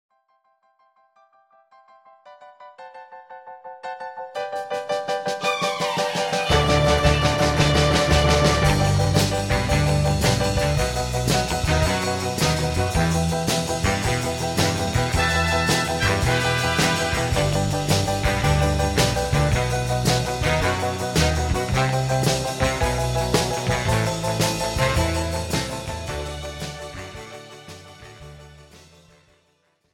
This is an instrumental backing track cover.
• Key – G
• Without Backing Vocals
• No Fade